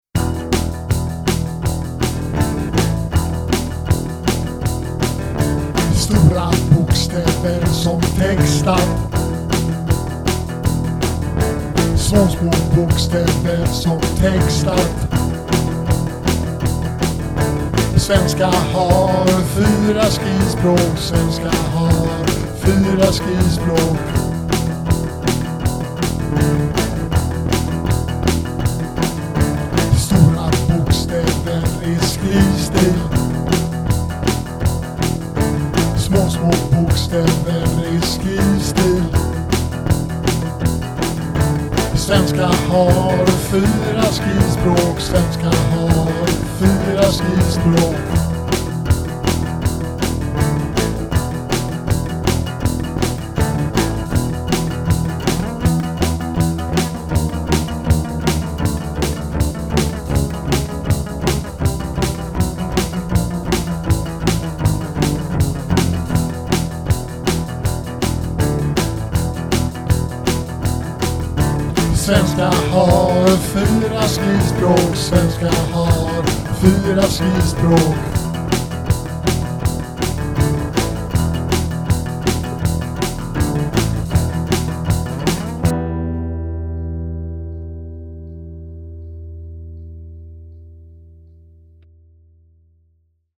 Kom snabbt på stick som höll sig inom treackordsramen.